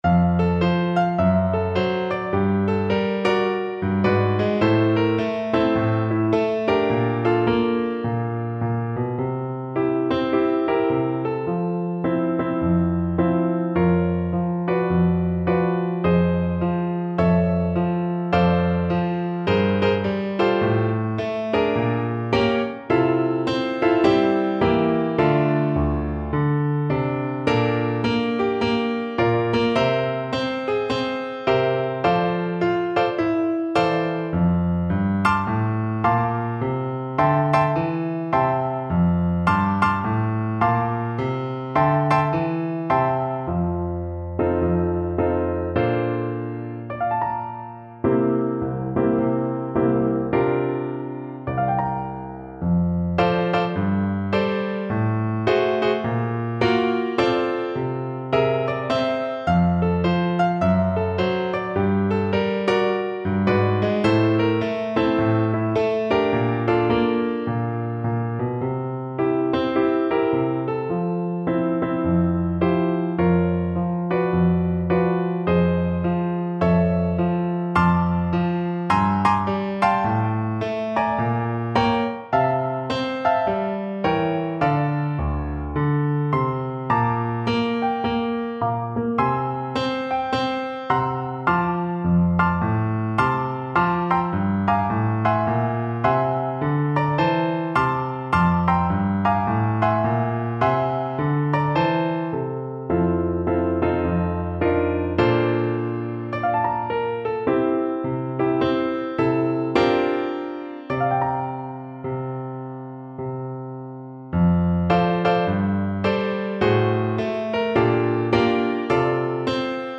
Fast swing =c.140